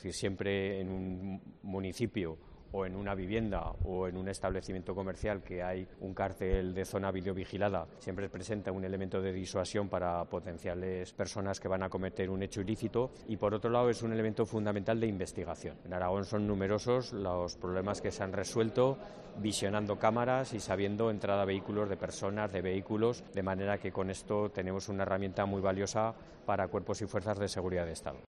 El delegado de Gobierno en Aragón habla de las videocámaras
La Mañana en COPE Huesca - Informativo local